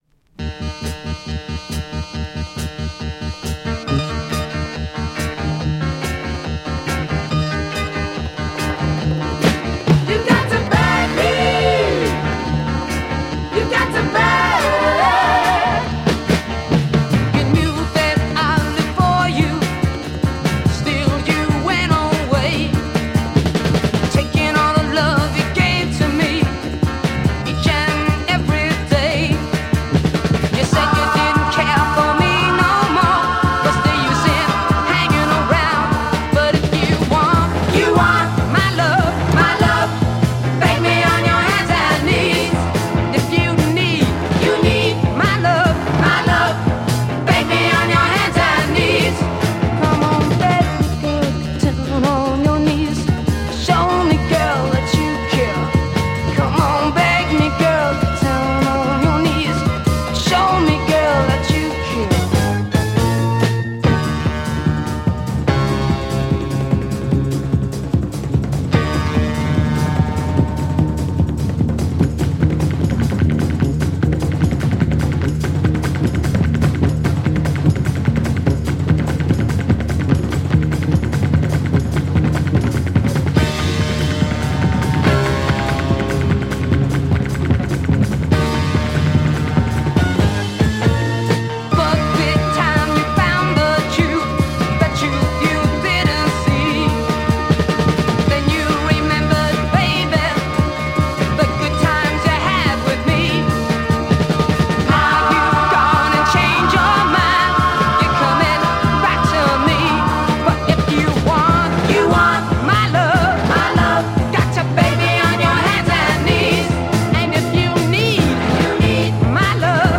Killer US Heavy Soul rock mod smasher!
Underrate US Psych proto glam dancer, rare French press !